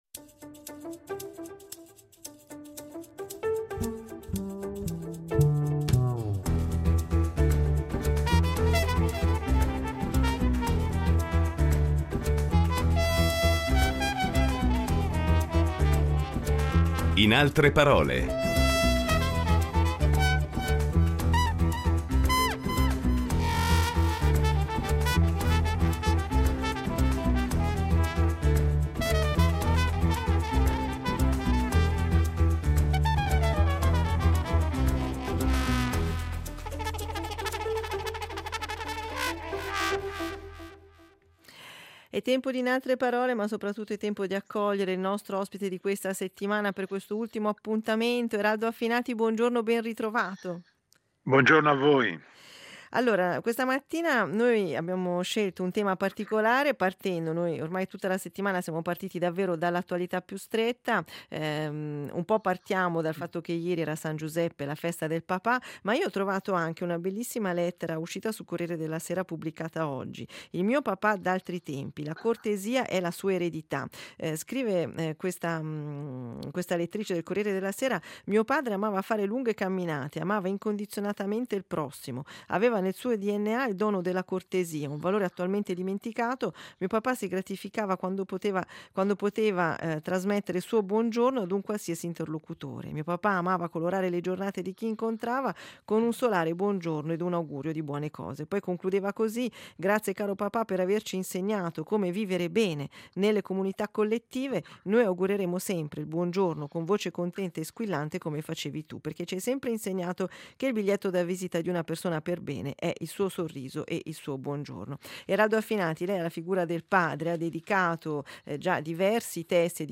Commento d’attualità con lo scrittore e insegnante